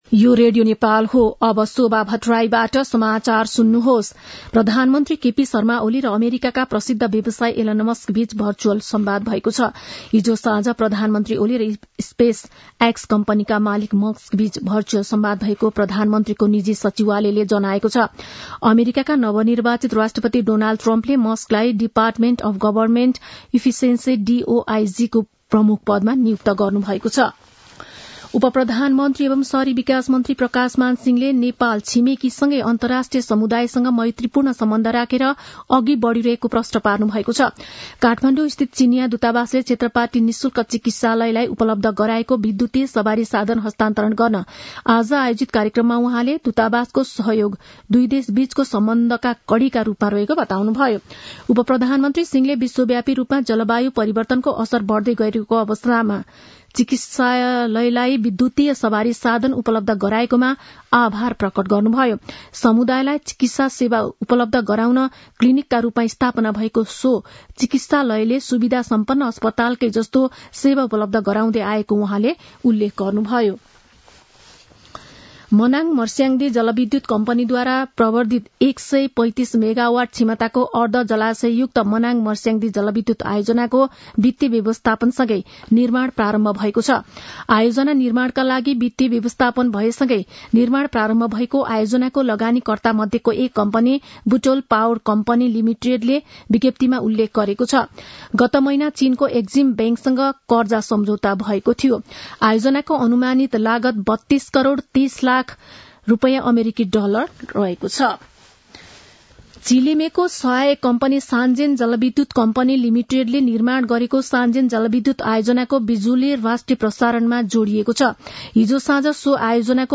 मध्यान्ह १२ बजेको नेपाली समाचार : ९ मंसिर , २०८१
12-am-nepali-news.mp3